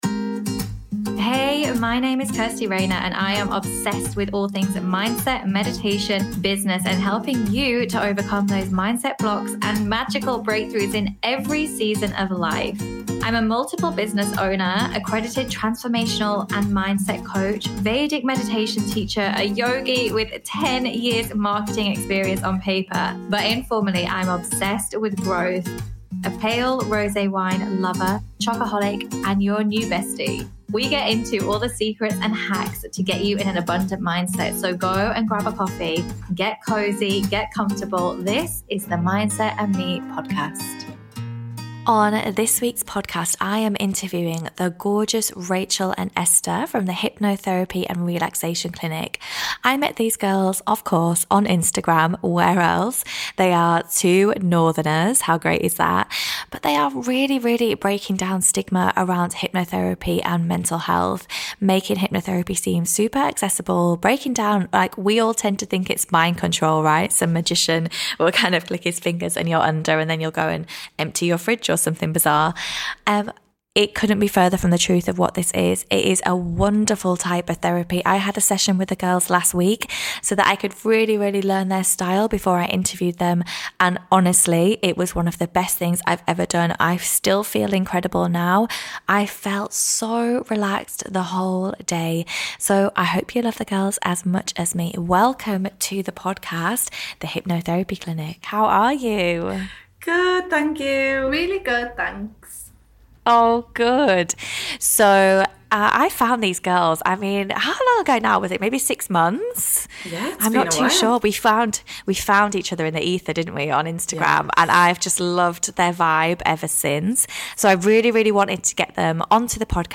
This episode is eye opening and I hope you enjoy this conversation as much as I did.